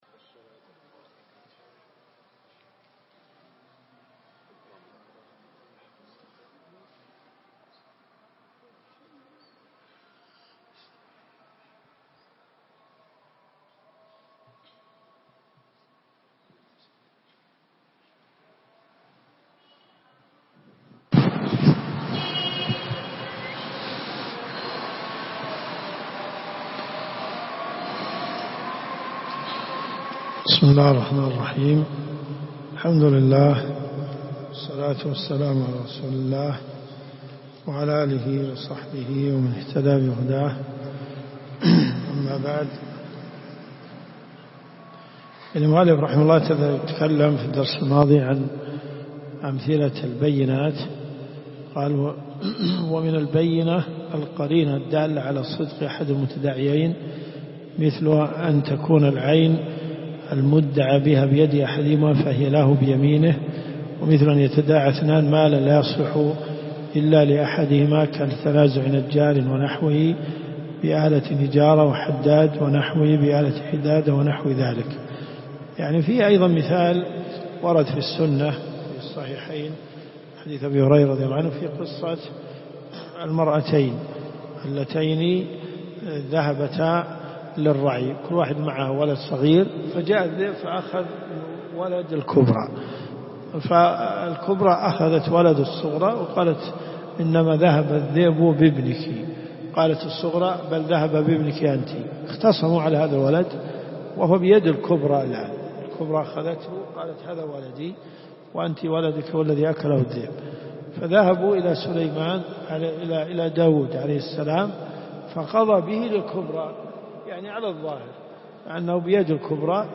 الرئيسية الكتب المسموعة [ قسم الفقه ] > (1) منهج السالكين .